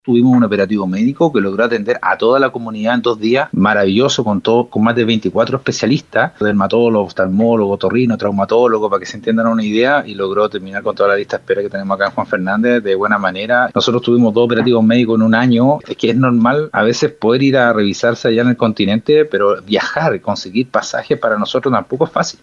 cu-operativo-medico-juan-fernandez-alcalde.mp3